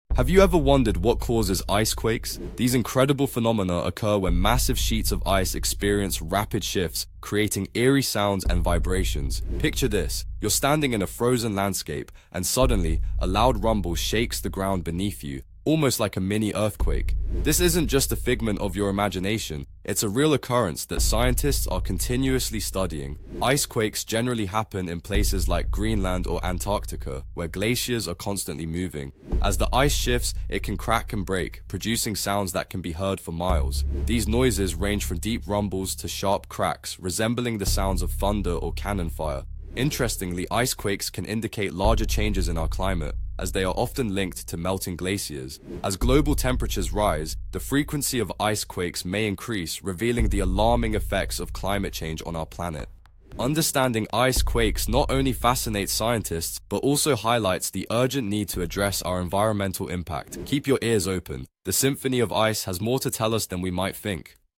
These sudden bursts of sound from frozen landscapes are more than just noise. Dive into this chilling phenomenon!